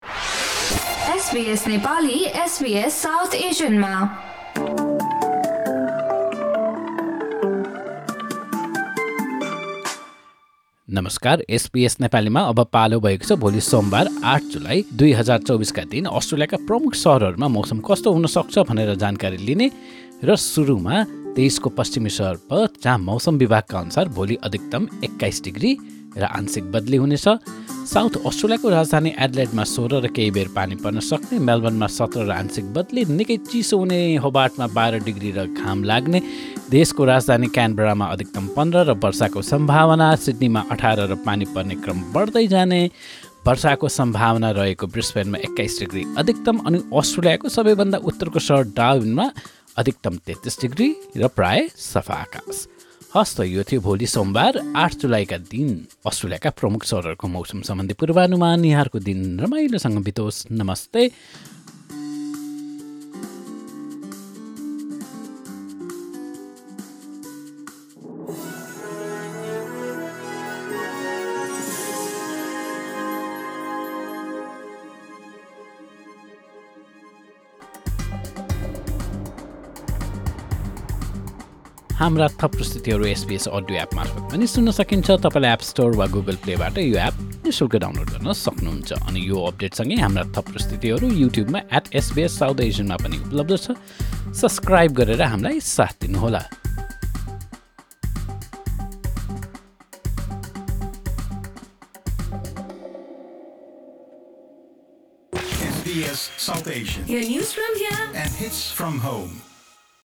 A quick Australian weather update in Nepali language.